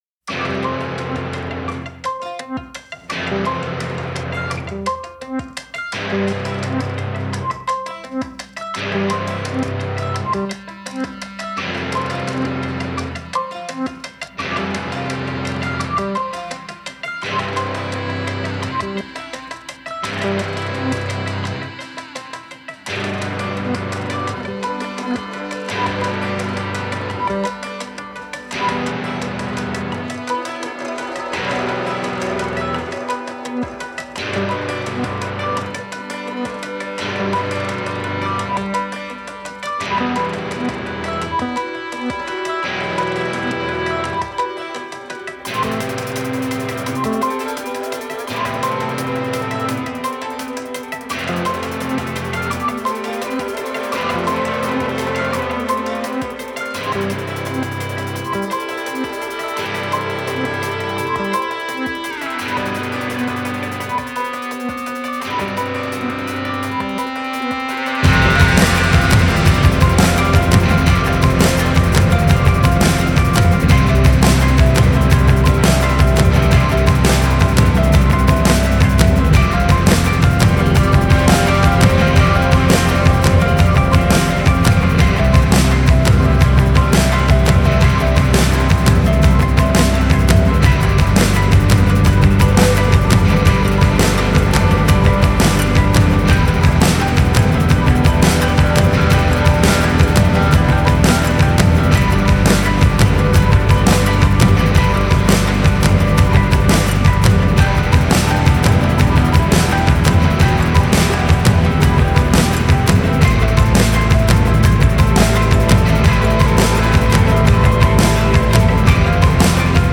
Post rock